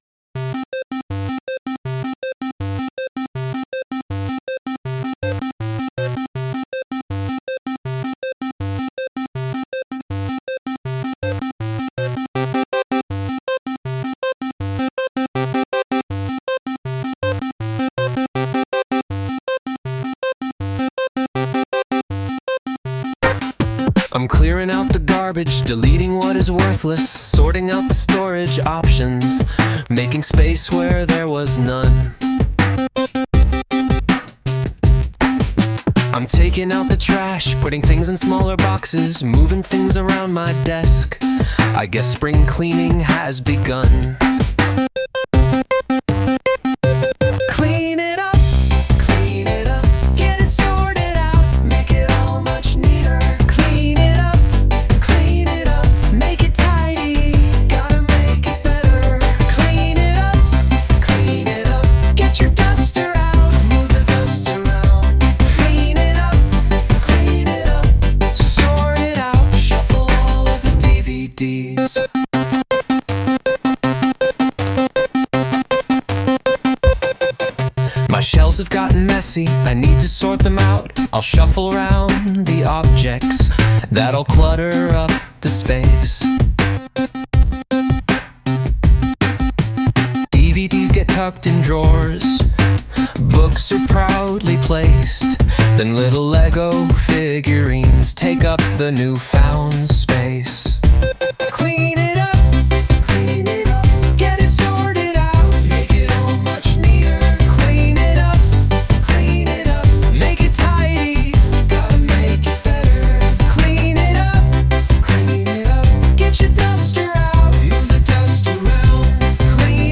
Sound Imported : Deconstructed Plumbing
Sung by Suno